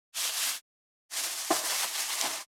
645コンビニ袋,ゴミ袋,スーパーの袋,袋,買い出しの音,ゴミ出しの音,袋を運ぶ音,
効果音